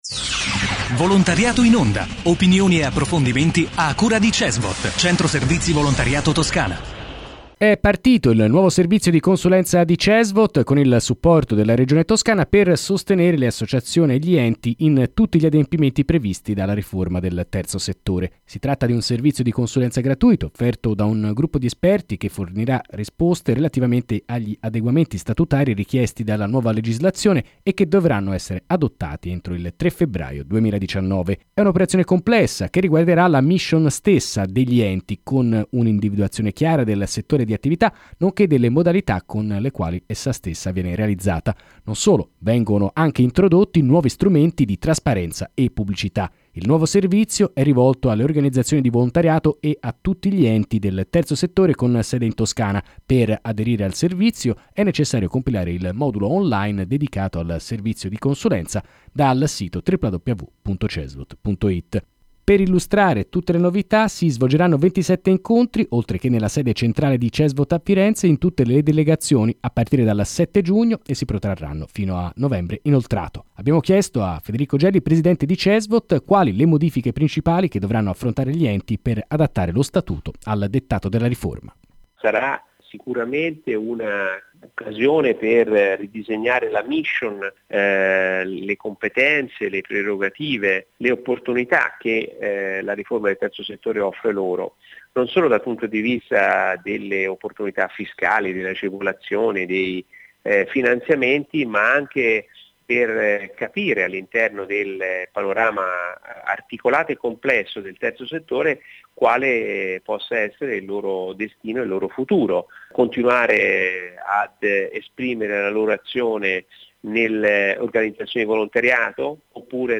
Intervista a Federico Gelli, presidente Cesvot